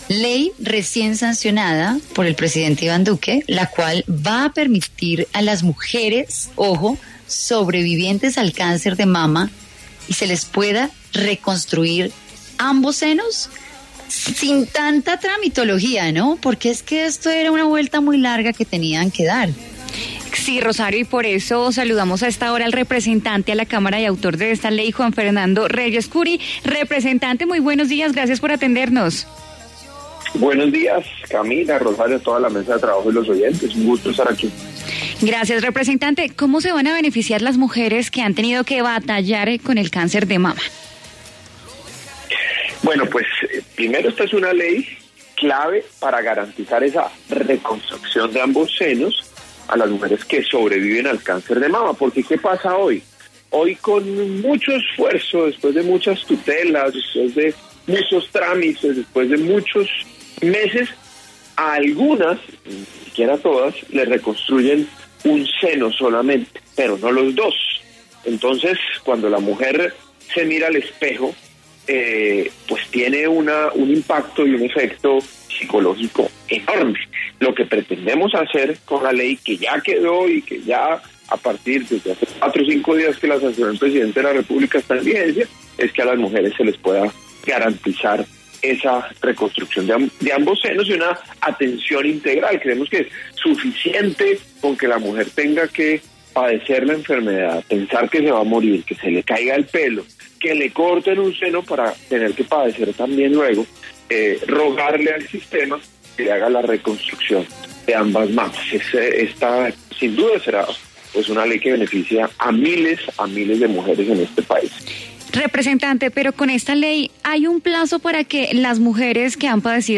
Juan Fernando Reyes Kuri, representante a la Cámara, explicó en W Fin de Semana los detalles de la ley que sancionó el presidente Iván Duque.
En entrevista con W Fin de Semana, Juan Fernando Reyes afirmó que “esta es una ley clave para garantizar la reconstrucción de los senos de las mujeres recuperadas del cáncer. Esta sin duda es una ley que beneficia a miles de mujeres”.